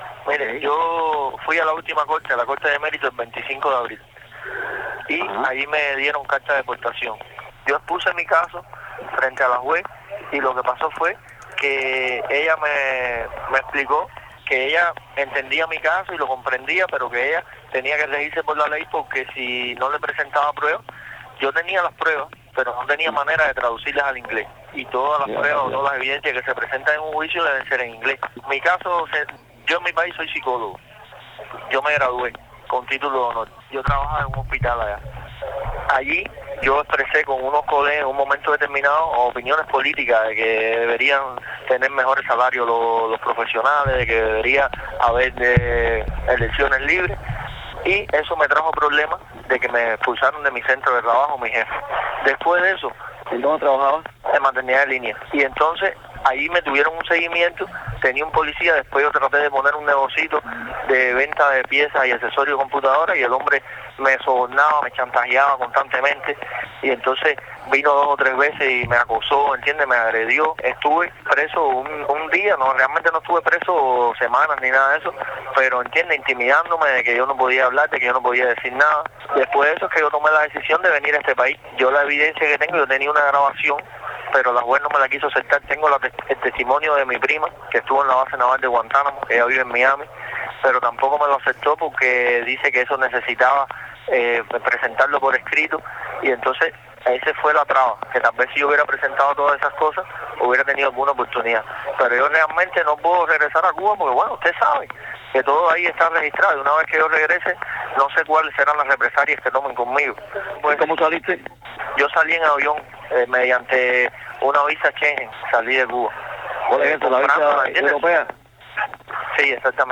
Testimonio de migrante cubano